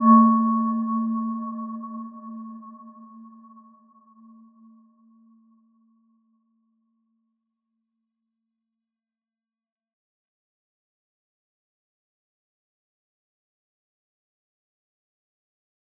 Aurora-C4-f.wav